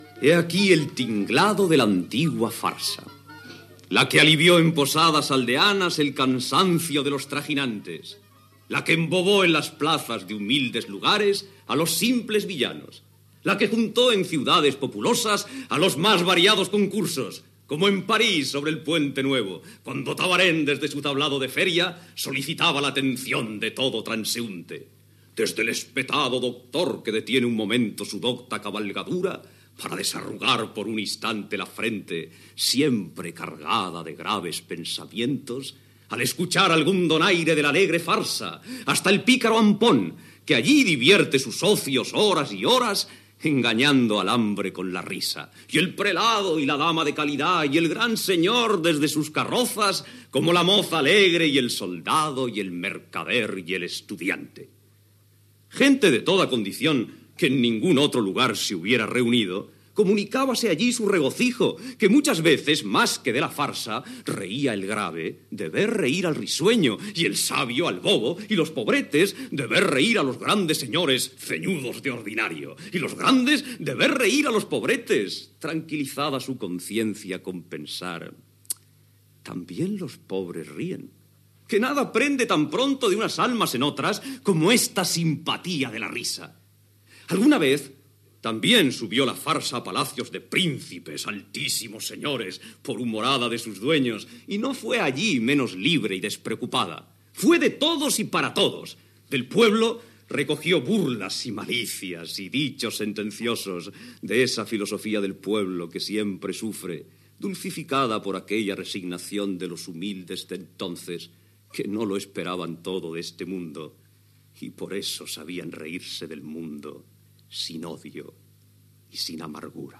Monòleg de l'obra "Los intereses creados", de Jacinto Benavente.
Ficció